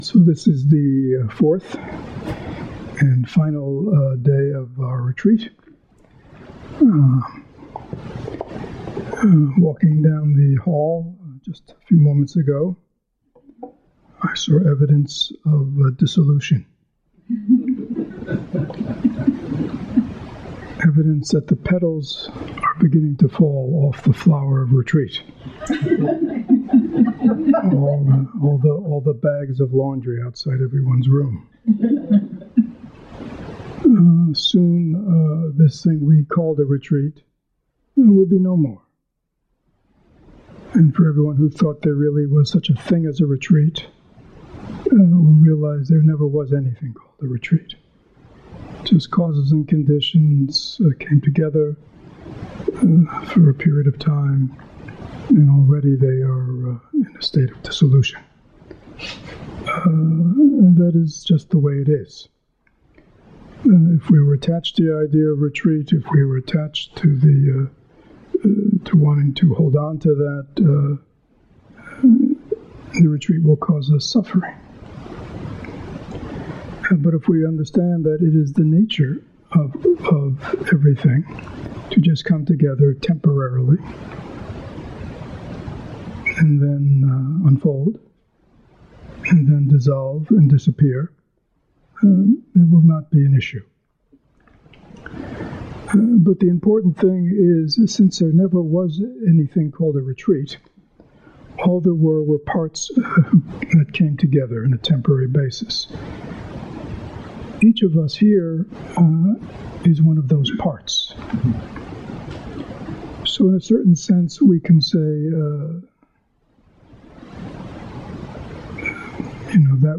Four-day Retreat | October 2014